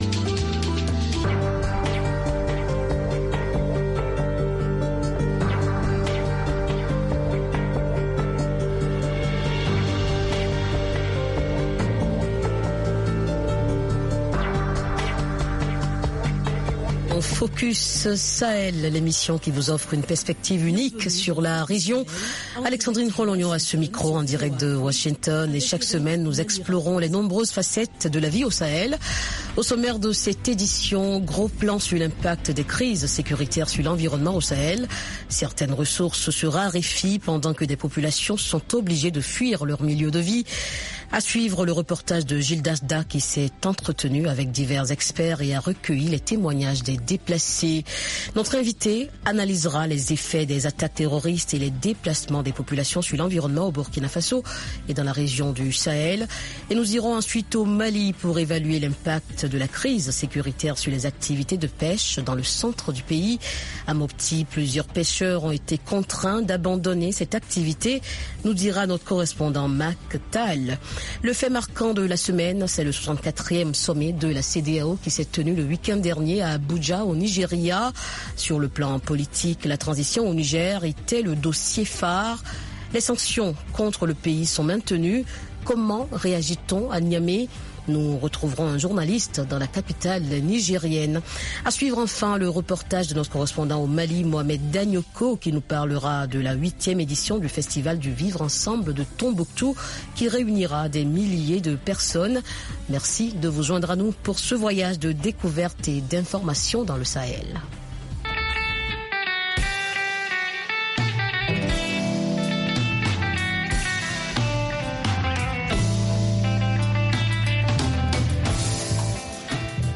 - Entretien avec des experts et des déplacés.